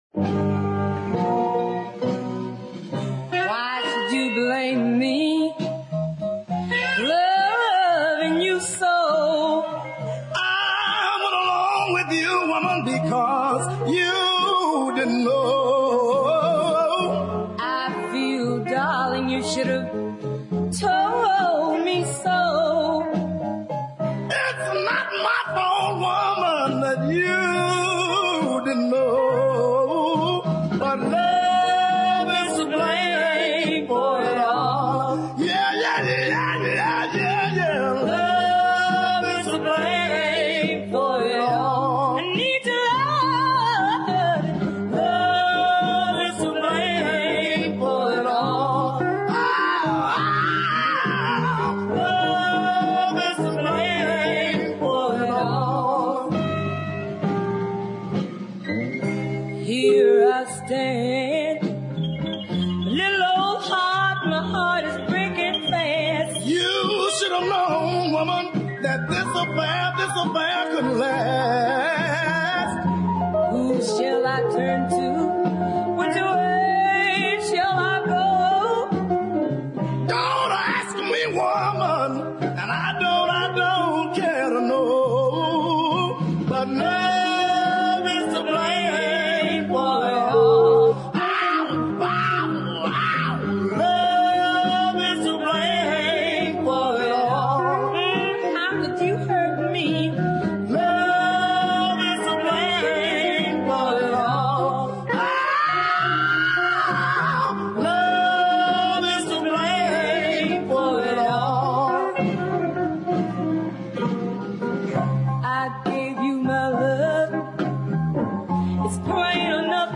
wonderful blues ballad
screaming, harsh singing